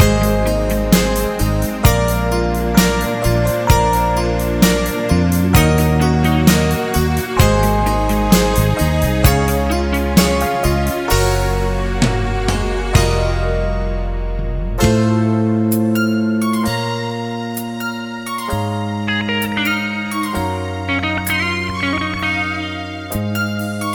Two Semitones Down Pop (1980s) 3:48 Buy £1.50